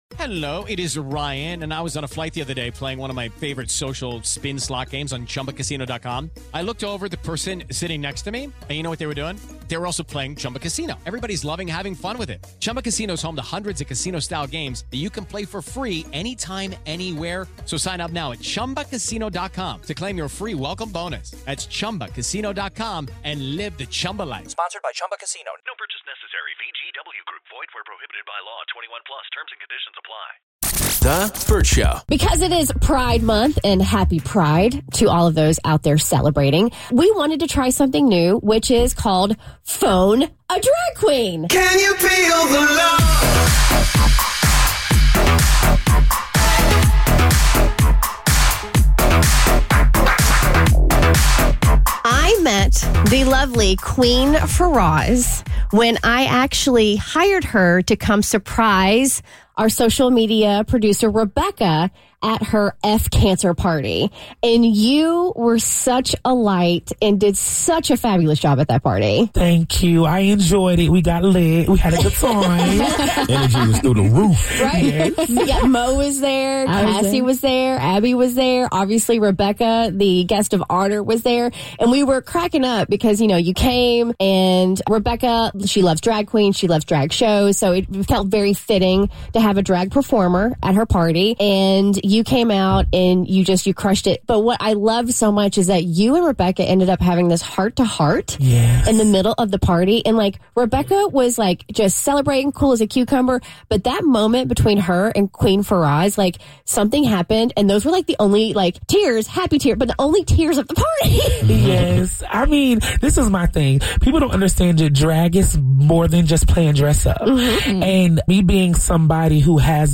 joined us in studio to talk fashion, relationships and how to navigate this thing called life!